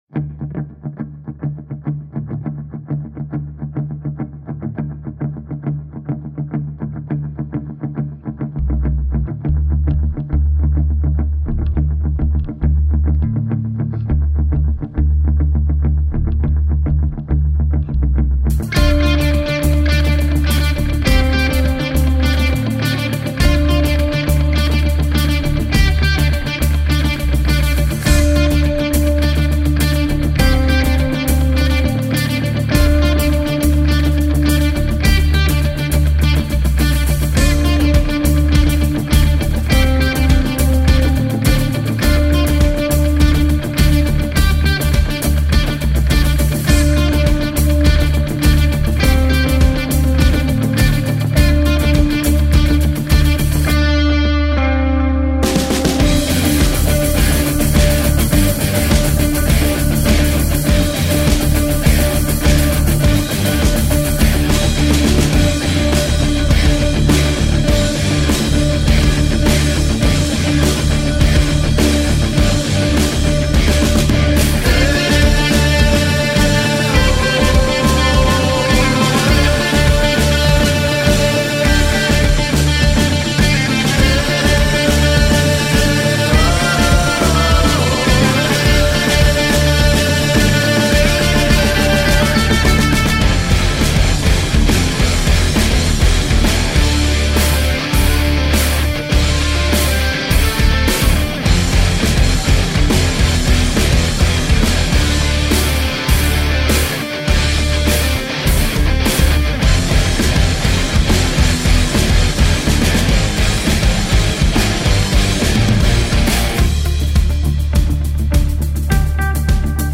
post-rock and metal band